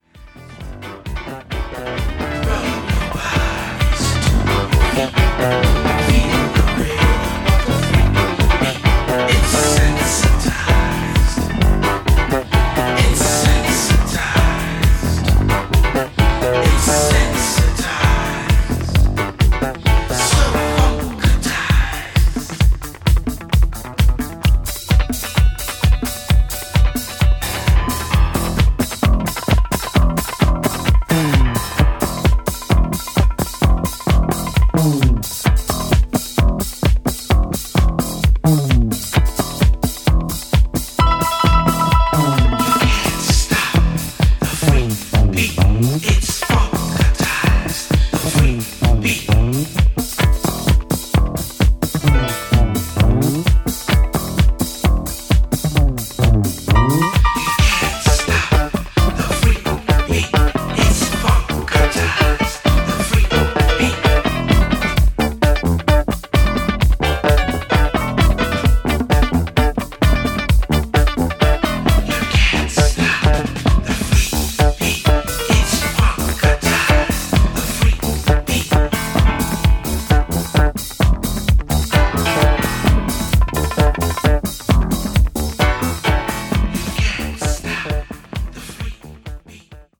New Release Disco Classics Soul / Funk